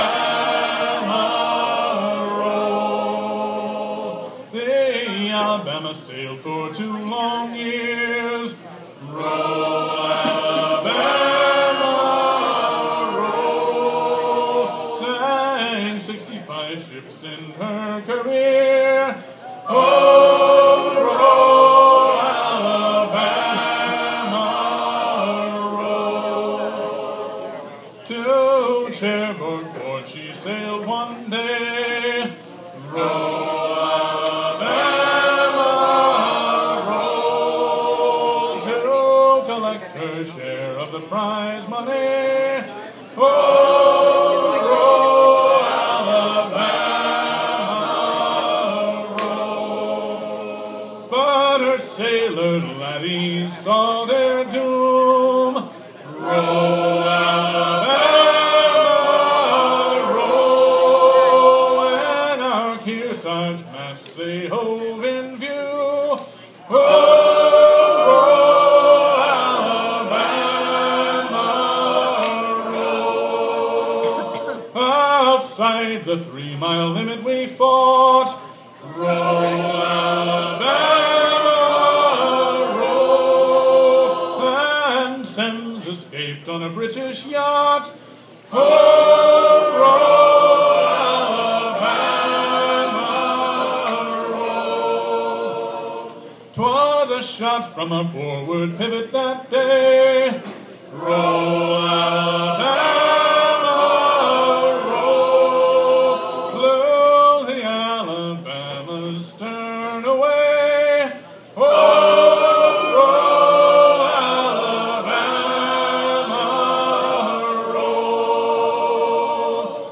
We sat down at a picnic table and listened to some of the live music.
Singing at theTugboat Roundup
The group singing in that clip said they practice at the “Angry Penguin”, so we decided to stop in our way home for a bite.
SingingAtTheTugboatRoundup.m4a